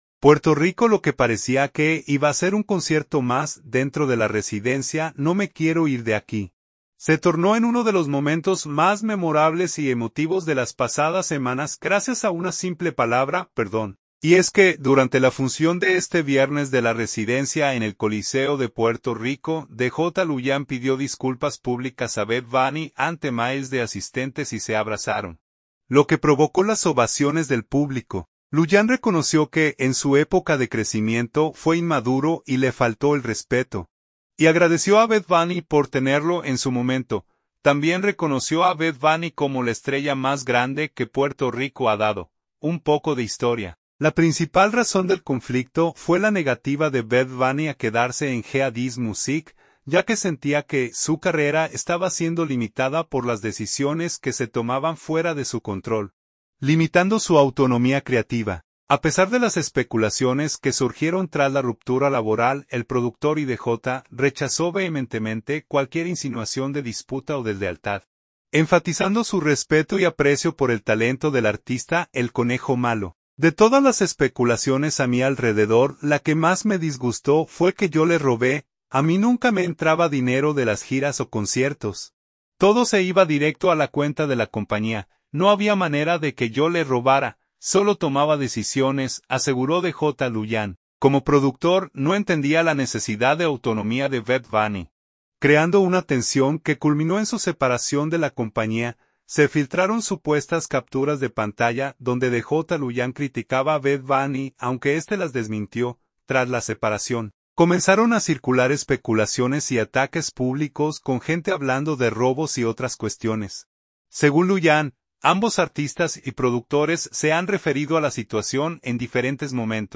Y es que durante la funsión de este viernes de "La Residencia" en el Coliseo de Puerto Rico, DJ Luian pidió disculpas públicas a Bad Bunny ante miles de asistentes y se abrazaron, lo que provocó las ovaciones del público.